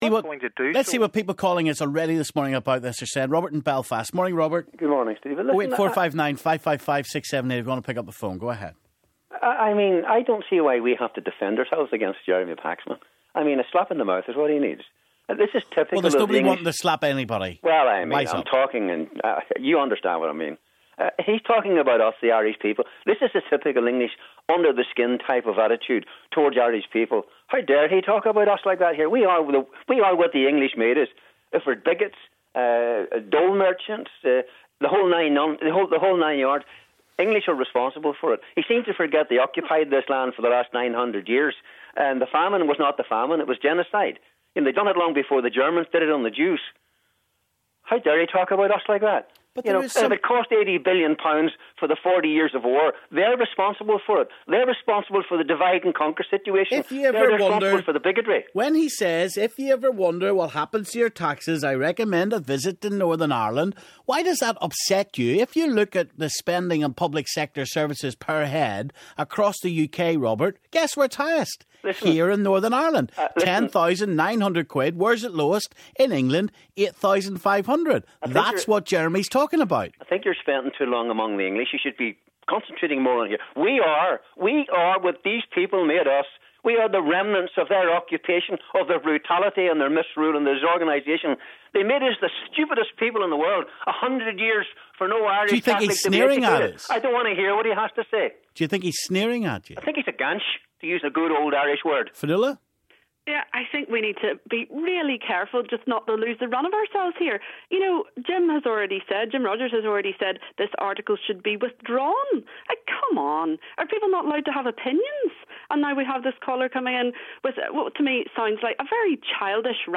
Callers clash over Paxman comments